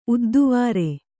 ♪ udduvāre